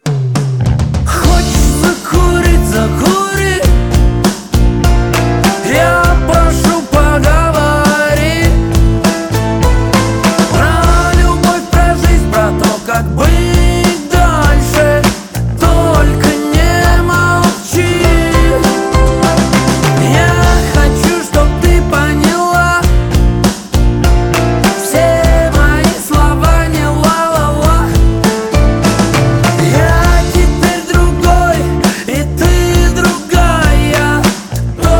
Жанр: Русская поп-музыка / Поп / Русские